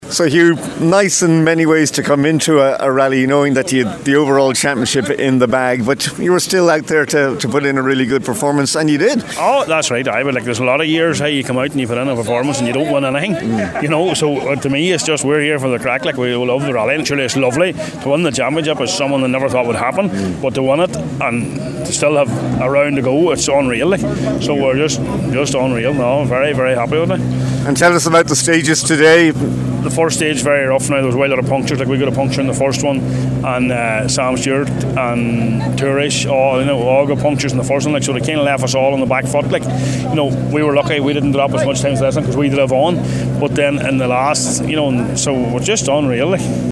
Donegal Forestry Rally – Reaction from the finish line